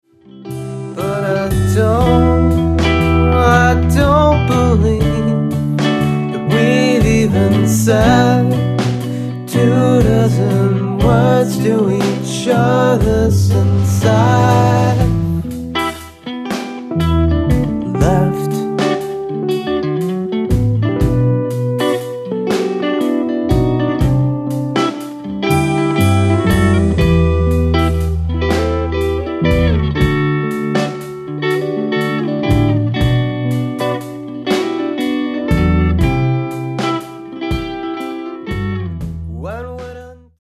Chapel Hill NC Rock Band